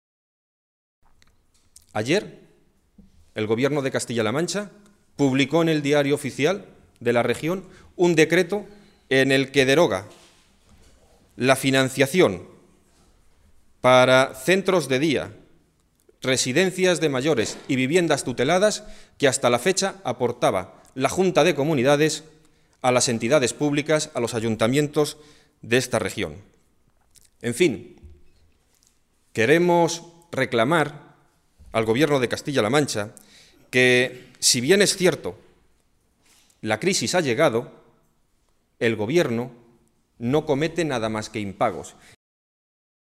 Alcaldes socialistas de las cinco provincias de Castilla-La Mancha comparecieron en rueda de prensa para informar sobre diferentes iniciativas políticas que se llevarán a cabo en los ayuntamientos de nuestra comunidad autónoma
Ha ejercido de portavoz Santiago García-Aranda, alcalde del municipio toledano de Villacañas.